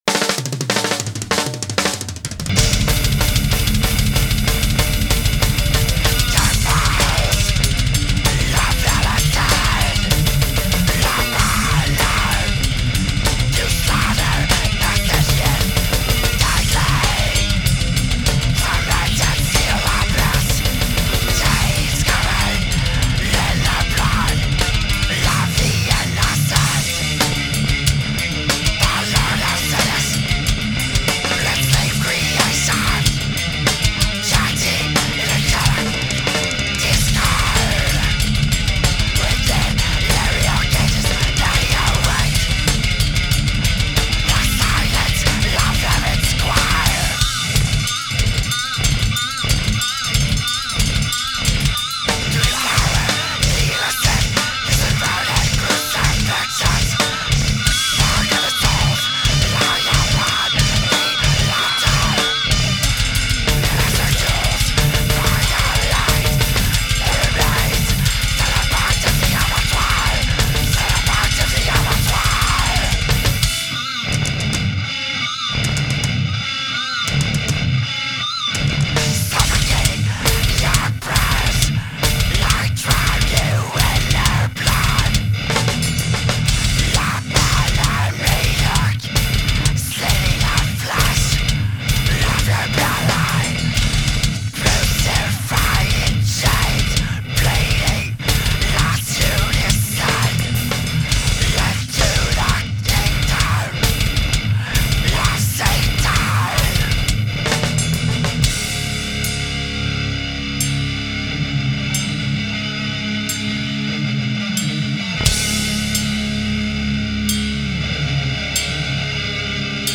Vocals
Guitar
Bass
Drums
Hardcore , Metal , Straight Edge